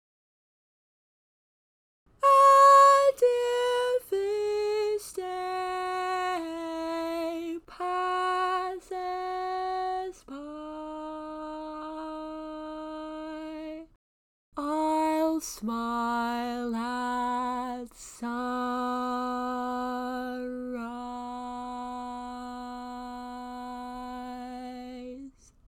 Key written in: B Major
Each recording below is single part only.